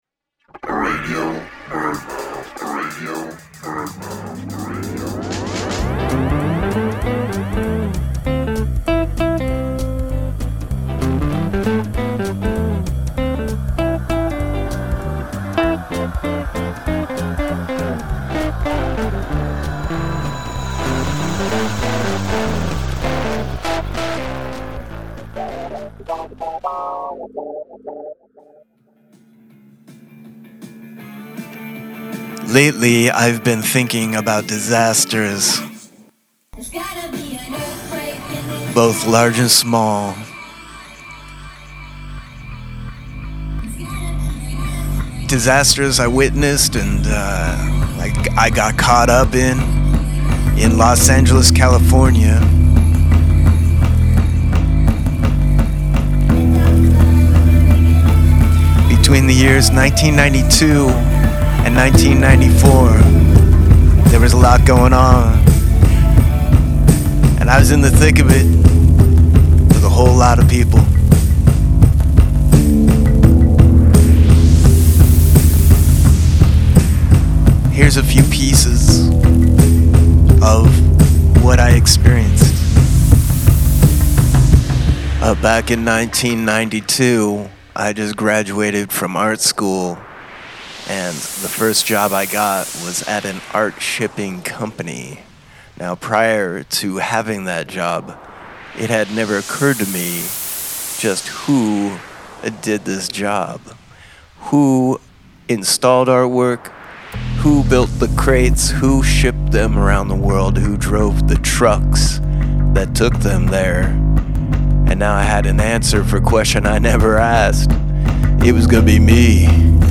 "Radio BirdMouth" is a loose-form audio road trip woven together from fragmentary spoken word narratives and sound manipulations.